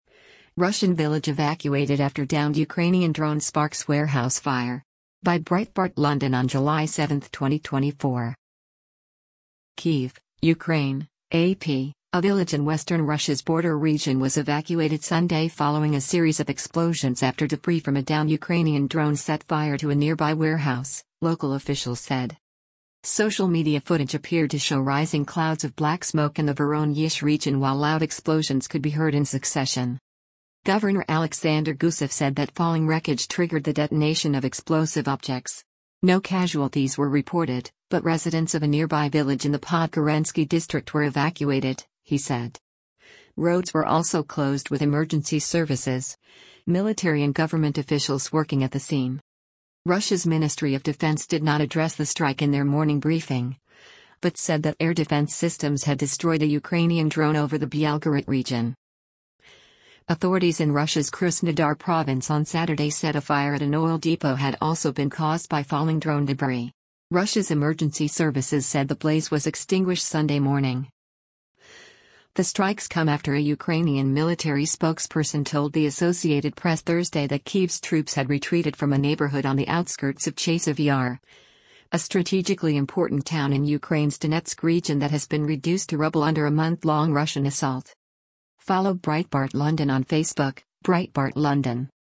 Social media footage appeared to show rising clouds of black smoke in the Voronezh region while loud explosions could be heard in succession.